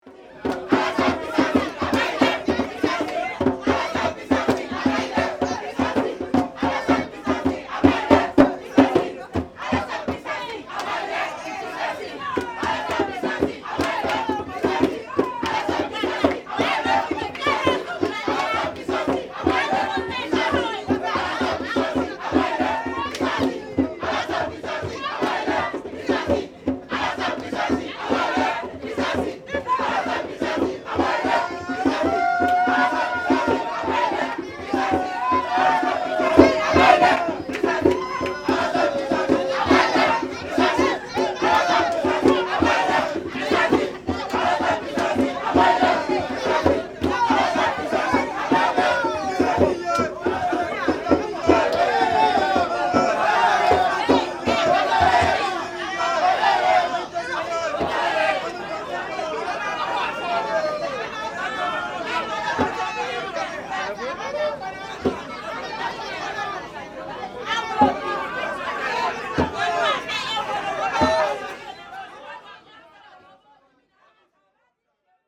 This is the sound of asylum seekers, mostly from West Africa, onboard the rescue ship the Ocean Viking in the Mediterranean, on the evening of the 27th of December 2022.
After what's known onboard the Ocean Viking as the "golden hour," where rescuees get the first rest they've had in a long time, the rescuees celebrated having survived the journey, utilising the drums kept onboard.